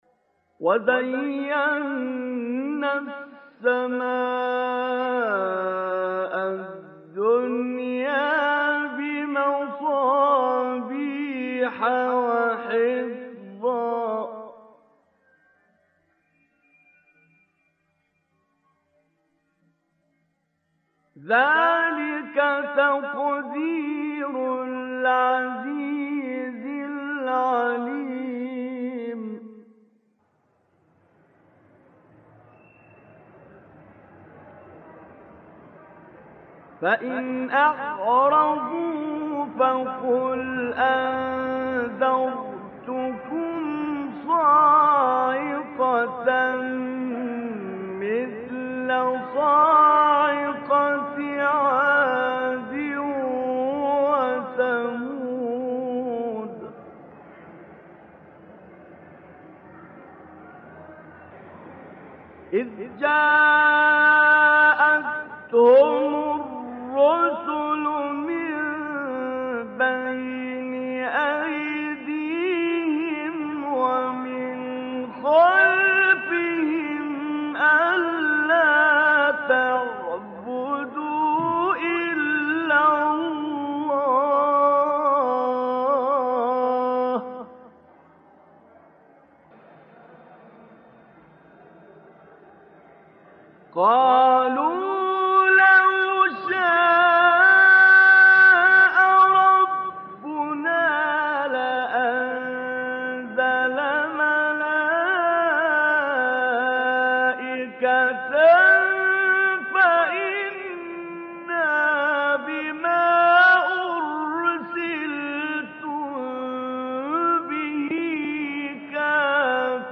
نهاوند استاد طنطاوی | نغمات قرآن | دانلود تلاوت قرآن